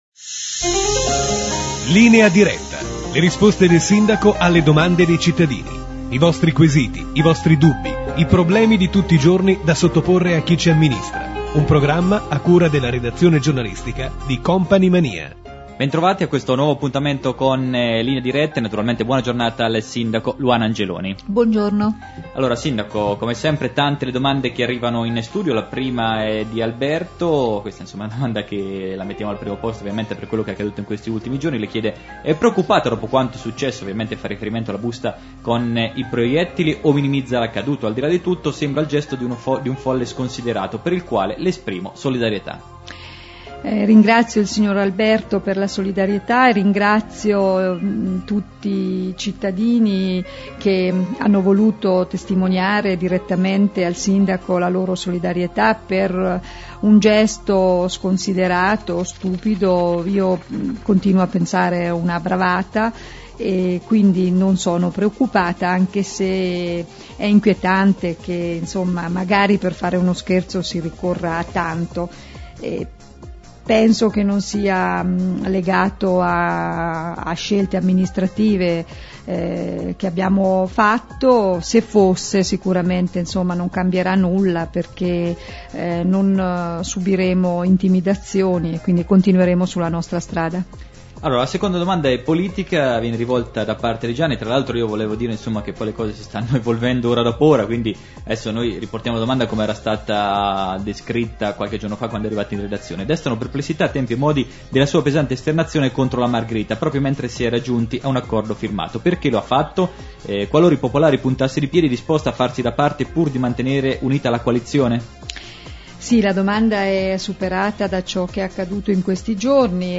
Scarica e ascolta Linea Diretta del 23/12/2004 : il programma radiofonico in cui il Sindaco Angeloni risponde alle domande dei senigalliesi. Il Sindaco ritiene una bravata la faccenda delle pallottole; lo screzio con la Margherita; i complimenti per la rotatoria di borgo Molino da parte di un ascoltatore; previsti finanziamenti per lo Stadio Comunale; votato un emendamento trasversale per la creazione di un punto a Senigallia per la Cremazione dei defunti; il nuovo piano degli arenili.